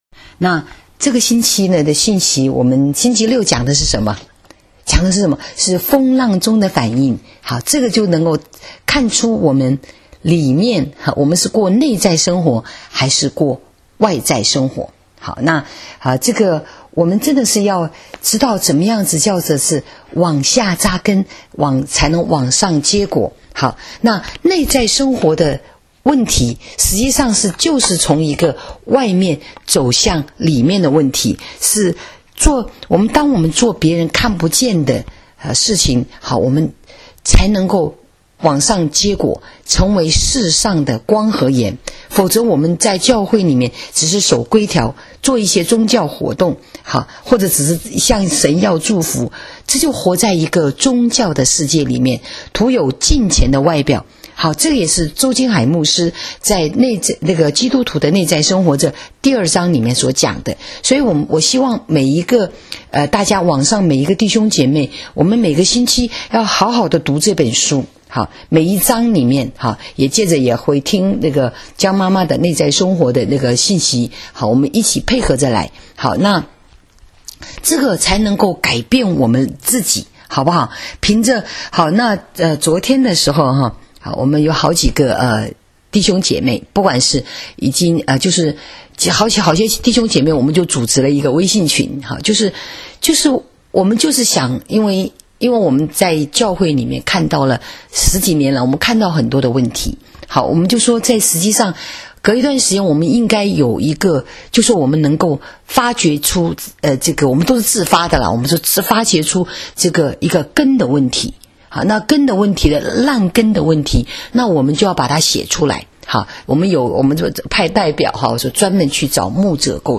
【主日信息】往下扎根、向上结果 6-9–19